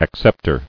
[ac·cep·tor]